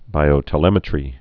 (bīō-tə-lĕmĭ-trē)